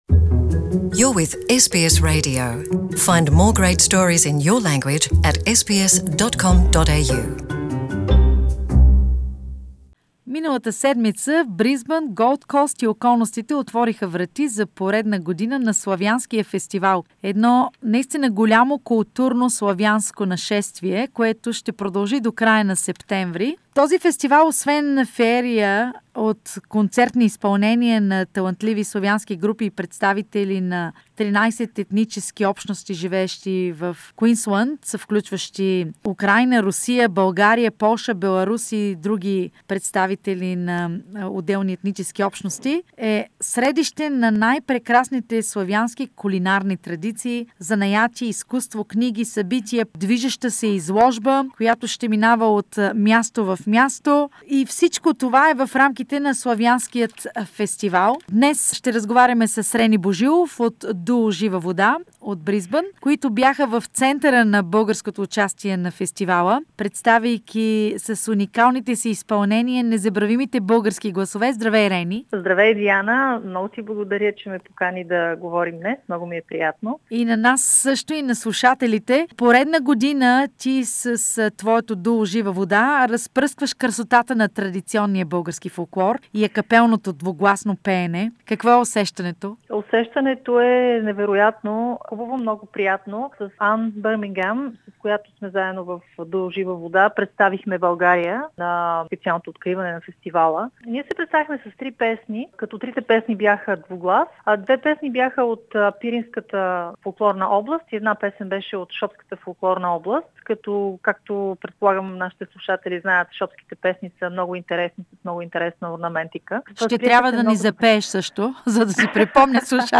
Queensland Slavonic festival opened its doors again for another year. 14 ethnic groups among them Bulgarian and "Zhiva voda" duo revealed the purity and beauty of the Bulgarian acapella singing - interview